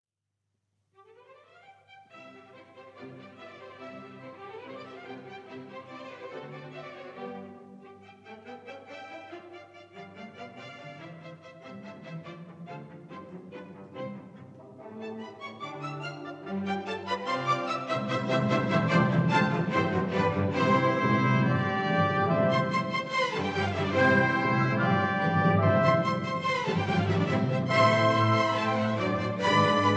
adagio - allegro molto vivace